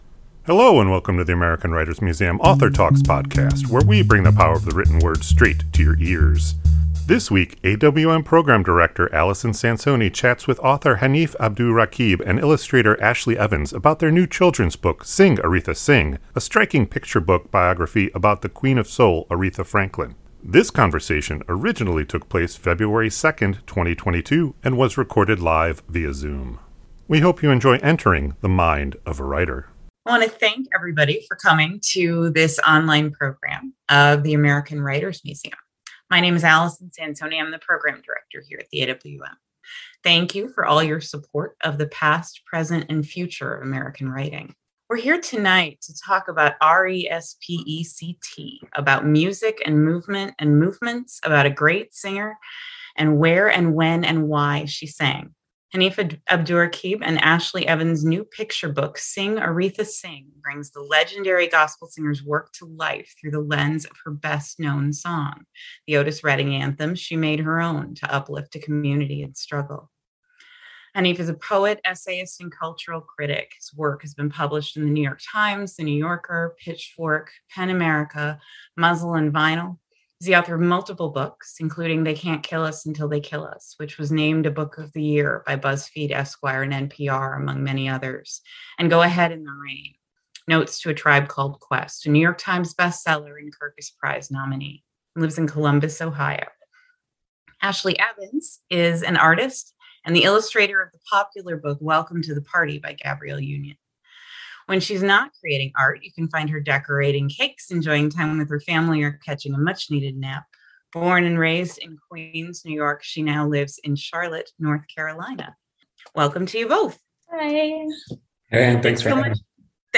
This conversation originally took place February 2nd, 2022 and was recorded live via Zoom.